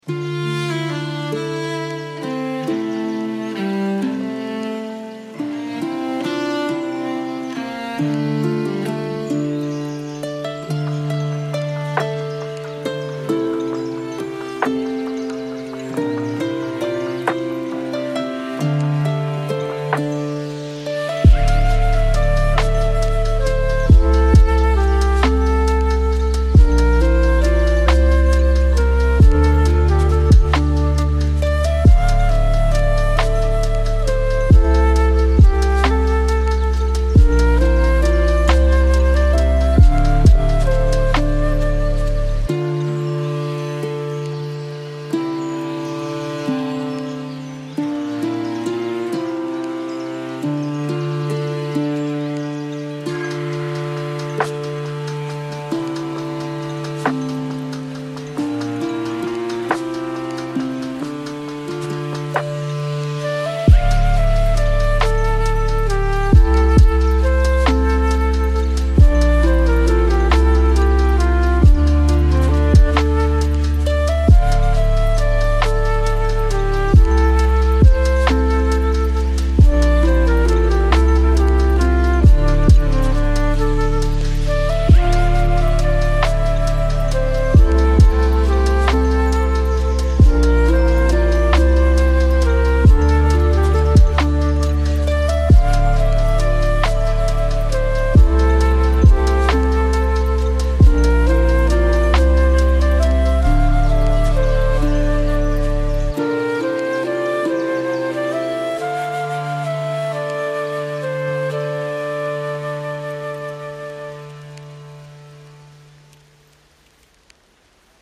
۱. لو-فای (Lo-Fi)